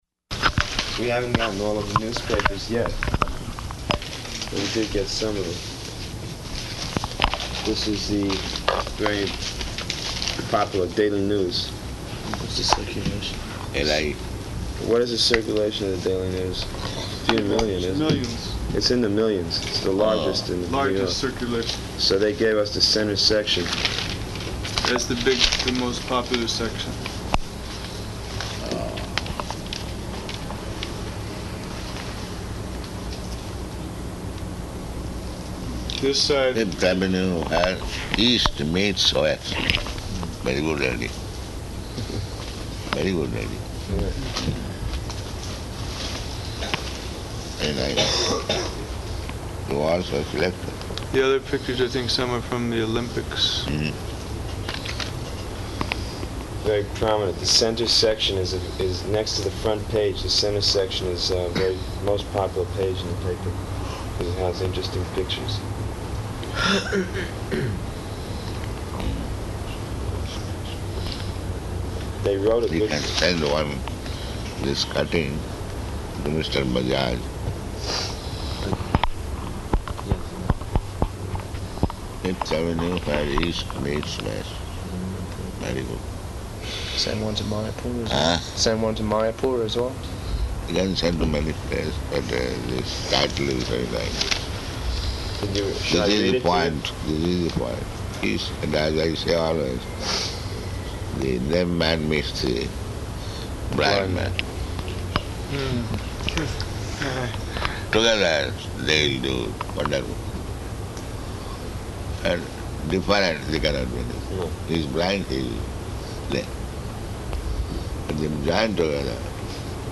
Room Conversation
-- Type: Conversation Dated